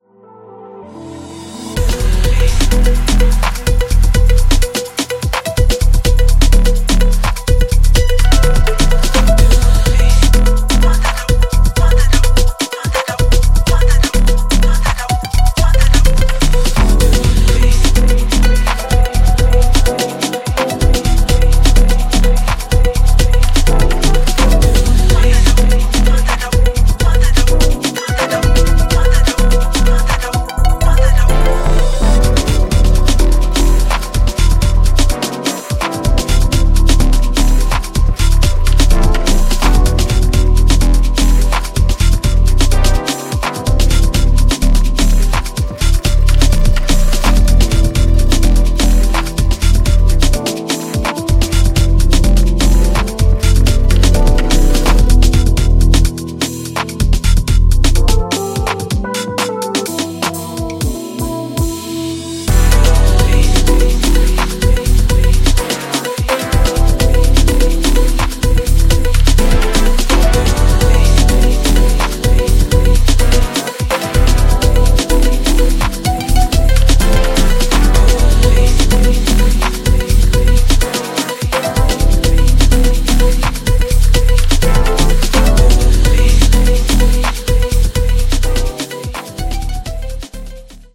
ジャンル(スタイル) DEEP HOUSE / BROKEN BEAT